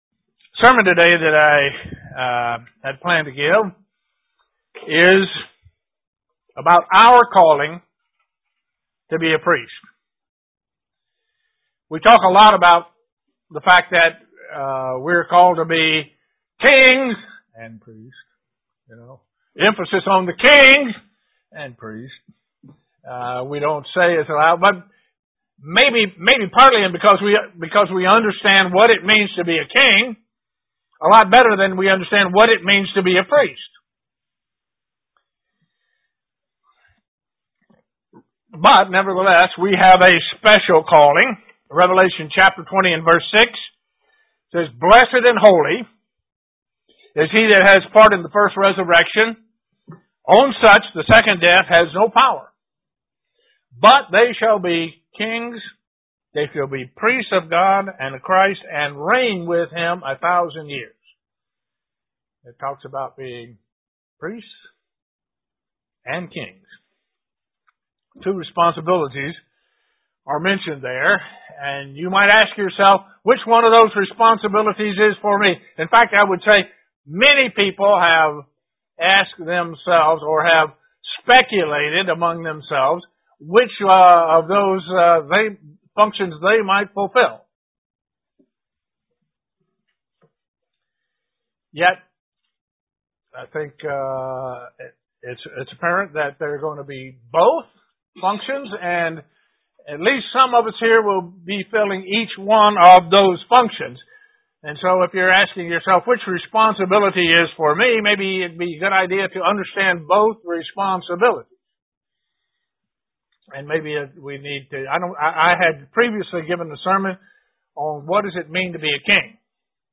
Given in Elmira, NY
Print Our calling and the functions of the priesthood UCG Sermon Studying the bible?